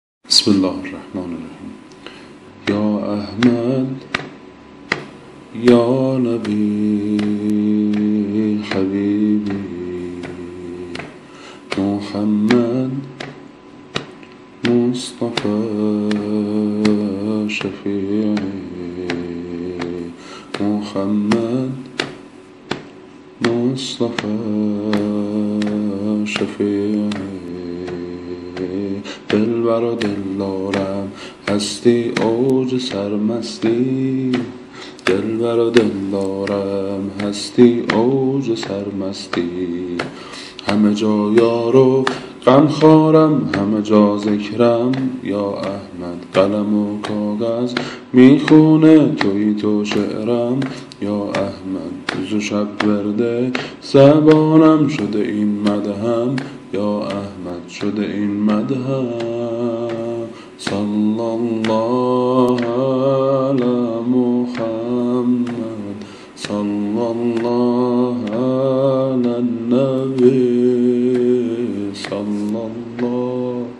سبک سرود مبعث.mp3